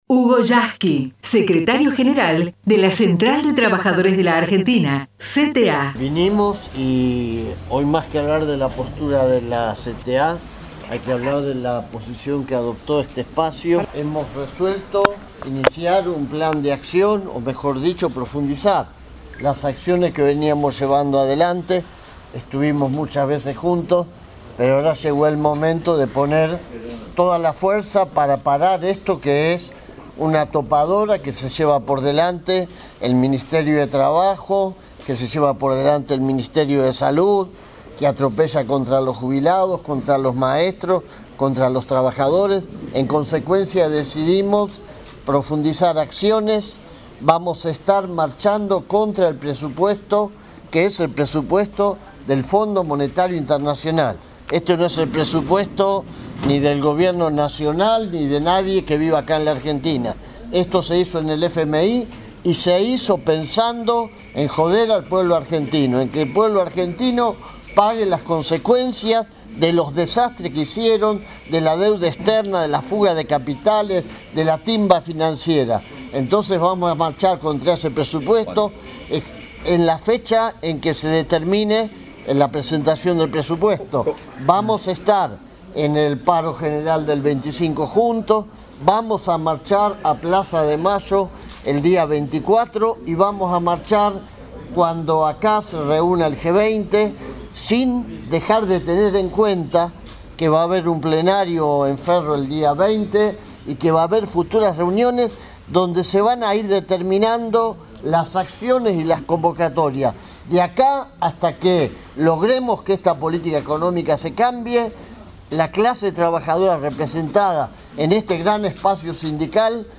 Conferencia de prensa del Frente Sindical para el Modelo Nacional y ambas CTA